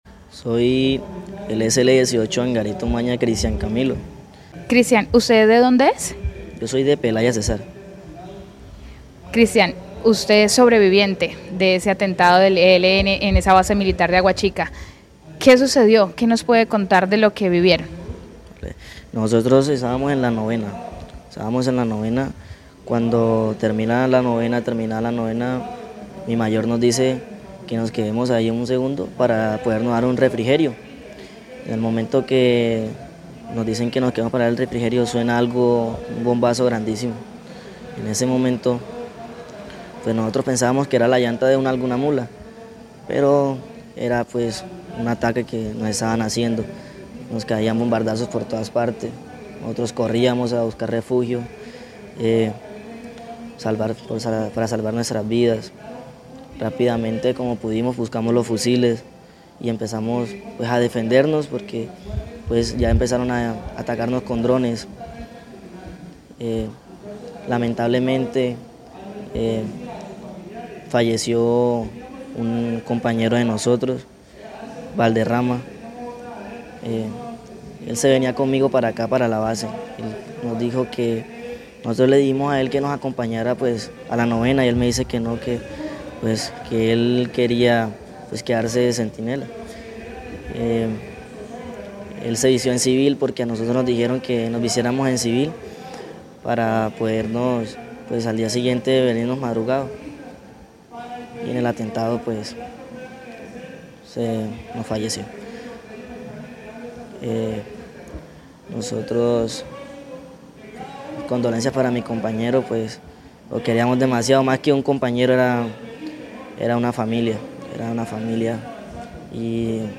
soldado sobreviviente ataque ELN